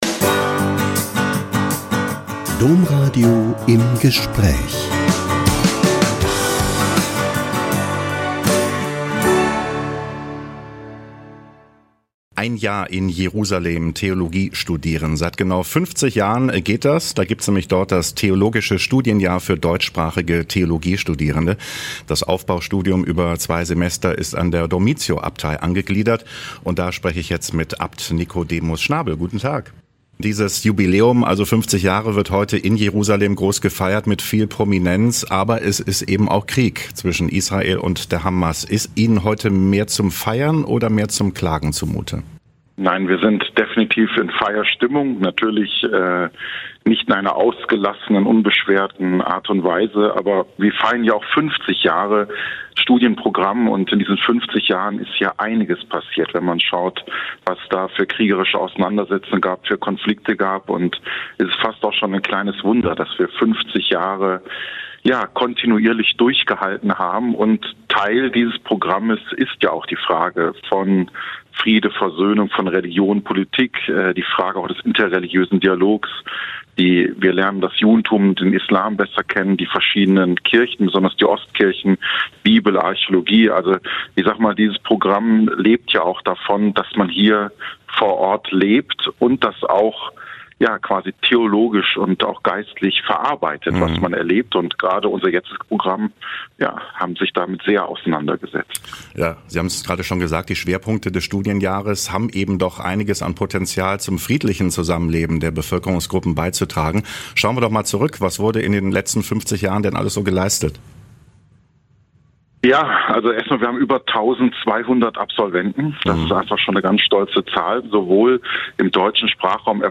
Theologisches Studienjahr in Jerusalem feiert Jubiläum - Ein Interview mit Abt Nikodemus Schnabel OSB (Abt der Dormitio-Abtei in Jerusalem)